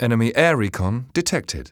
1 channel
enemyreconarrived.mp3